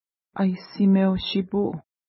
ID: 570 Longitude: -57.8100 Latitude: 52.0000 Pronunciation: a:jssi:mew-ʃi:pu: Translation: Inuit River Official Name: St. Paul River / Rivière St-Paul Feature: river Explanation: Uepushkueshkau flows into Aissimeu-shipu.